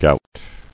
[gout]